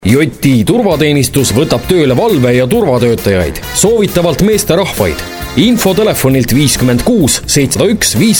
Professioneller estnischer Sprecher für TV/Rundfunk/Industrie.
Sprechprobe: Werbung (Muttersprache):
Professionell estnian voice over artist